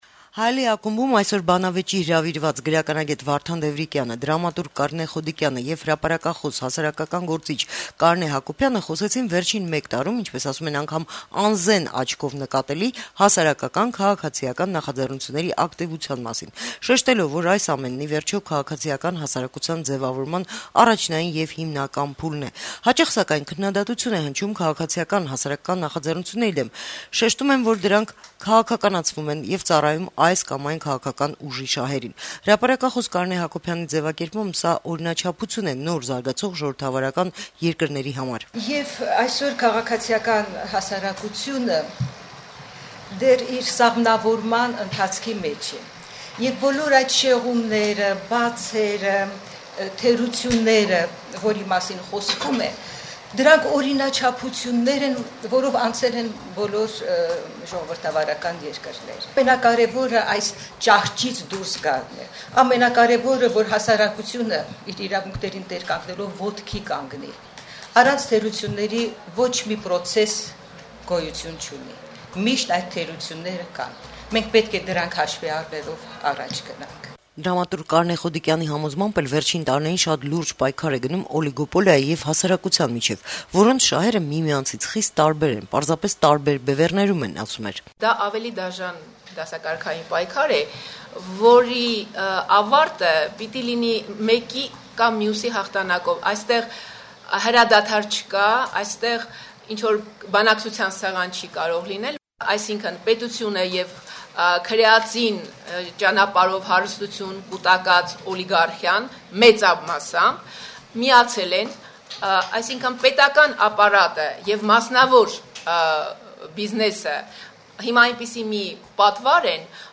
բանավեճը վերջին ժամանակներում ակտիվացած քաղաքացիական նախաձեռնությունների մասին բավականին թեժ ստացվեց: